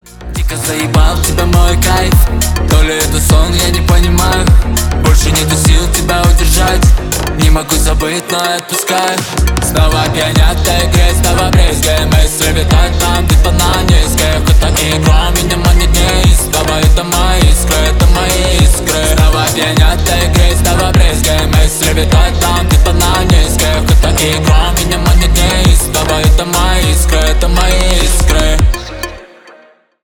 Рэп и Хип Хоп
клубные